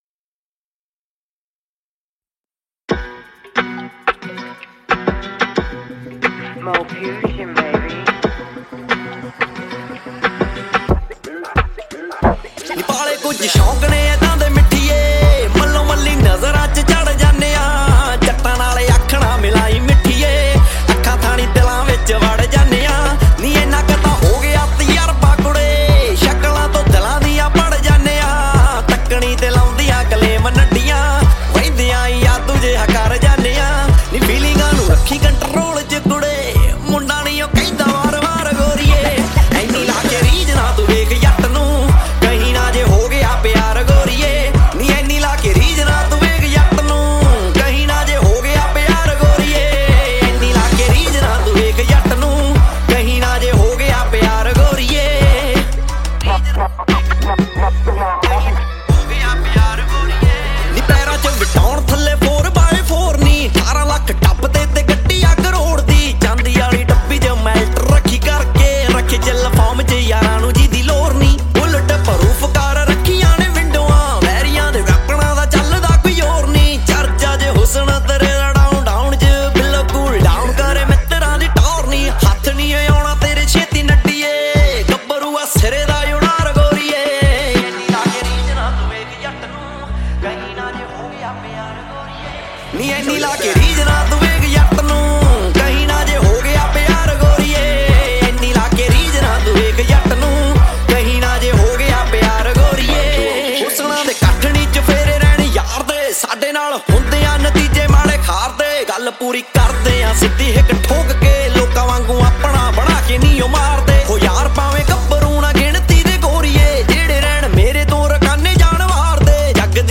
New Punjabi Song 2023